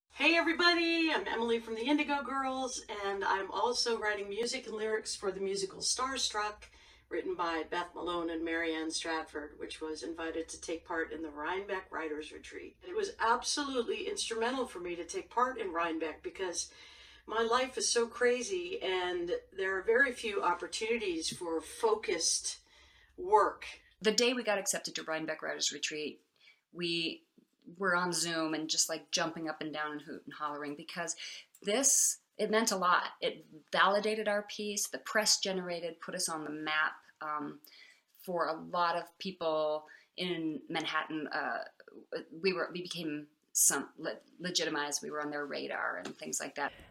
lifeblood: bootlegs: 2021-11-18: celebrating a decade of writers and new musicals - rhinebeck writers retreat 10th anniversary virtual fundraiser - zoom (emily saliers)
(captured from the zoom stream, the first twenty minutes are missing)